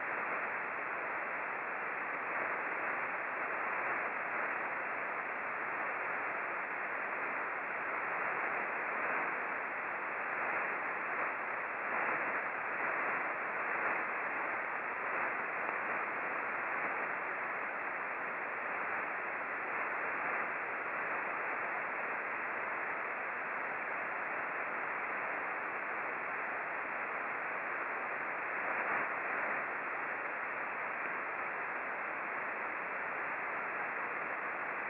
For this daytime experiment, we used the Icom R-75 HF Receiver tuned to 21.250 MHz (LSB). The antenna was a 3-element Yagi pointed 120 degrees true (no tracking was used).
The recorded bursts were mostly L-bursts.
Listen for L-bursts (swishing sound).